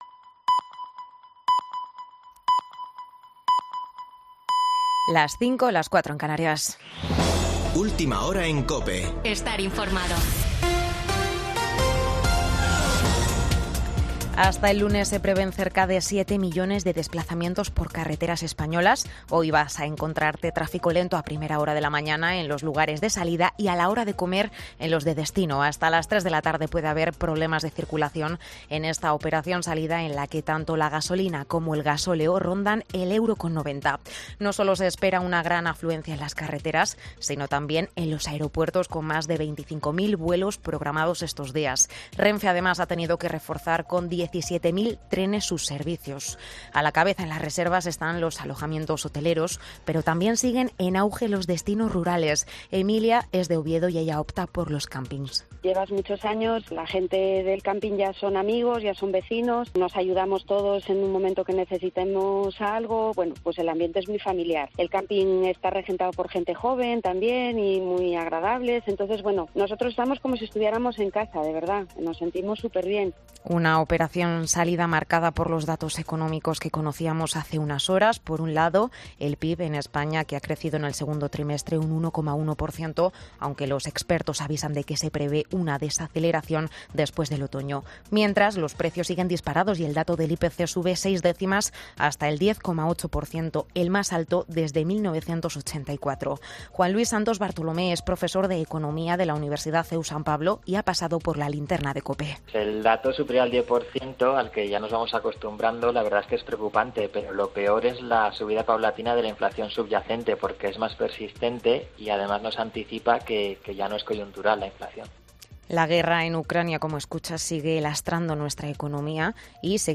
AUDIO: Boletín de noticias de COPE del 30 de julio de 2022 a las 05:00 horas